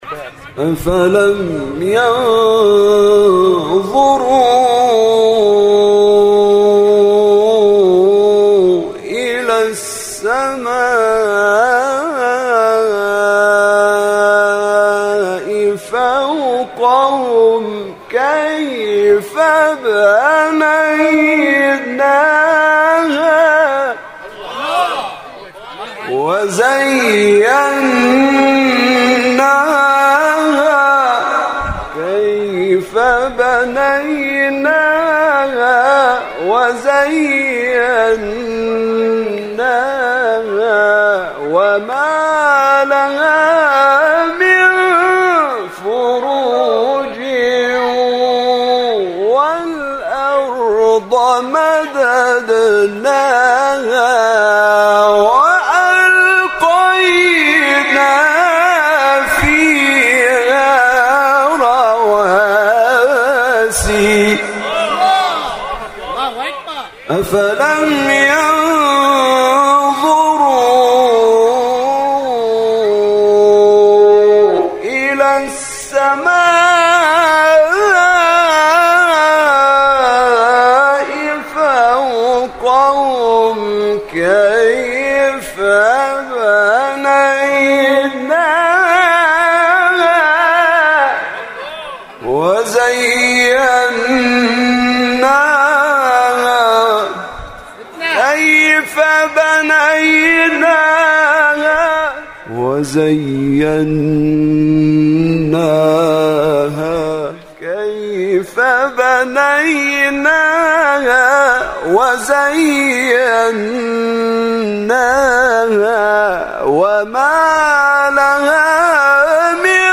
مقطع حزین عبدالناصر حرک 37 حرک | نغمات قرآن
سوره : ق آیه : 6-7 استاد : عبدالناصر حرک مقام : بیات